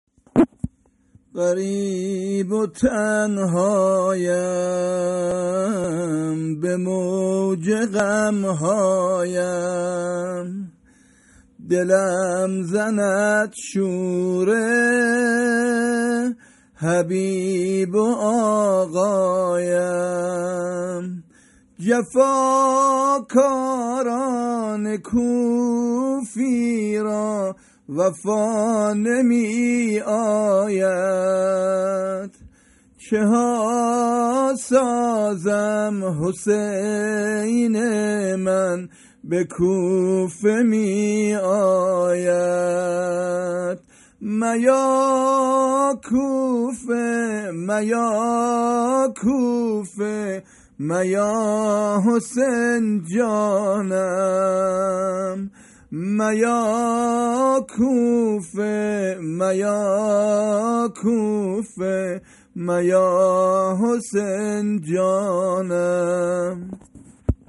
زمزمه ونوحه حضرت مسلم